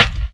SouthSide Snare Roll Pattern (19).wav